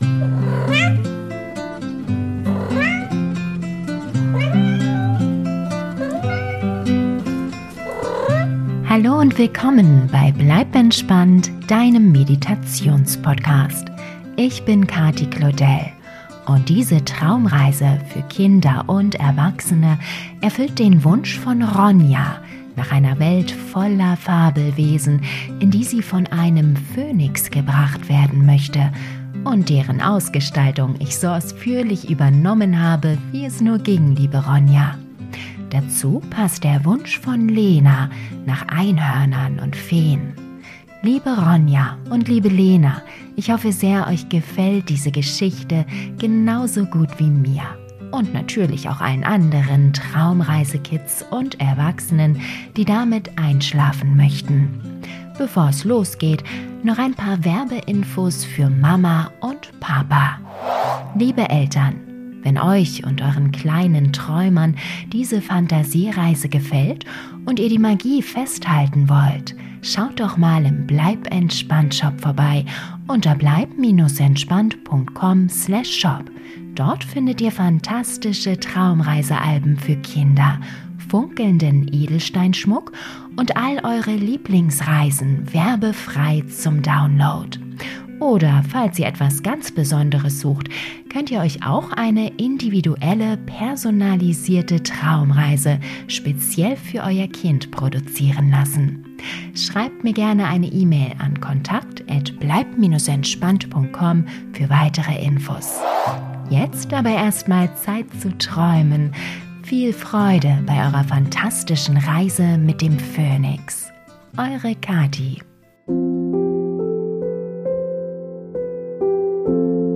Traumreise für Kinder & Erwachsene - Die phantastische Reise mit dem magischen Phönix - Geschichte zum Einschlafen ~ Bleib entspannt!